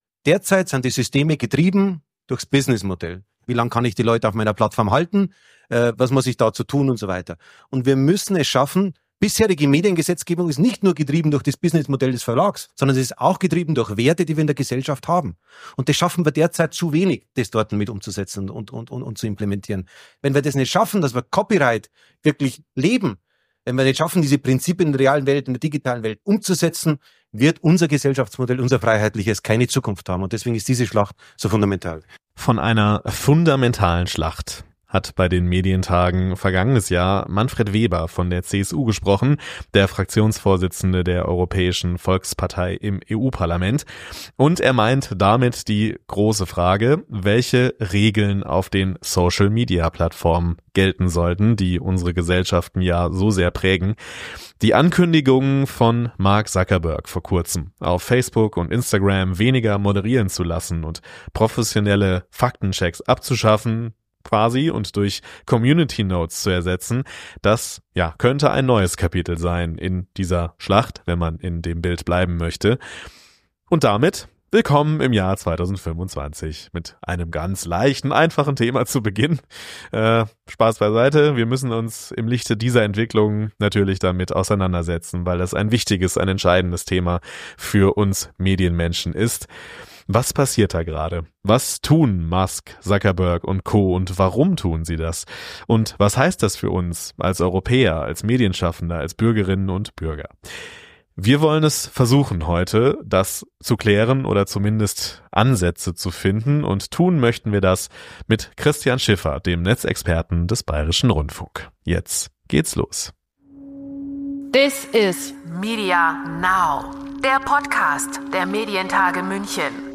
Es sind Expert:innen aus der Branche zu Gast, ihr hört Ausschnitte von Medienevents